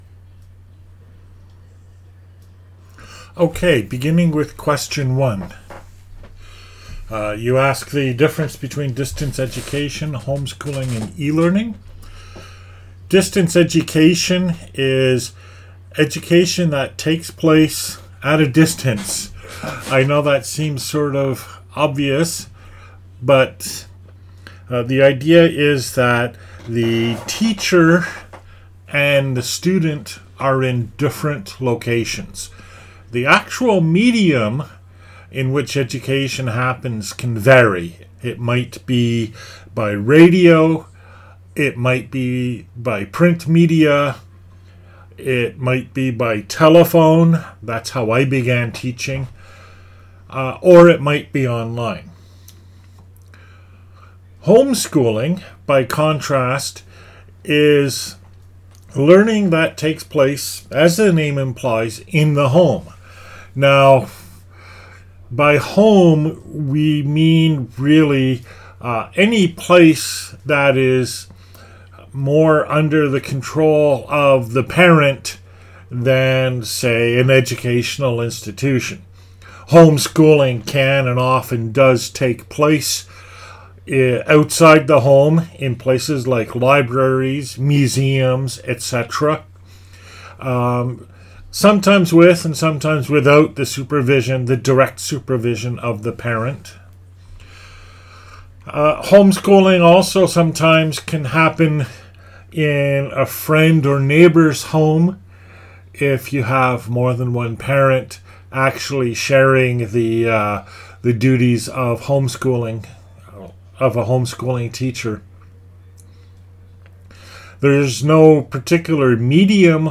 And also, my new Audio-Technica microphone arrived today.
I was speaking off the top of my head, without notes.
And here is the link to the original audio, also unedited.